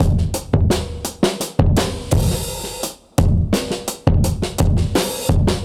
Index of /musicradar/dusty-funk-samples/Beats/85bpm/Alt Sound
DF_BeatA[dustier]_85-02.wav